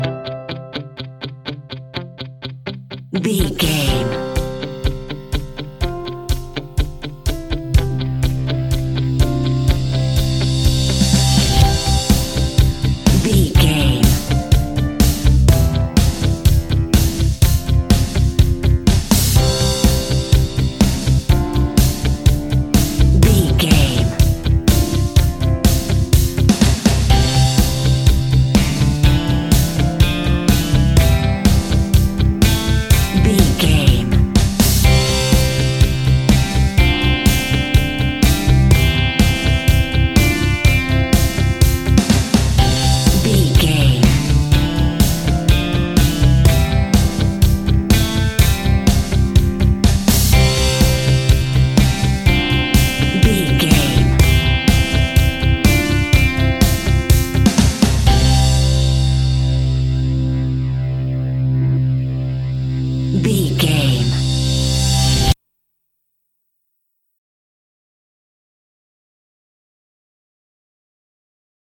Fast paced
Uplifting
Ionian/Major
indie pop
energetic
cheesy
instrumentals
guitars
bass
drums
piano
organ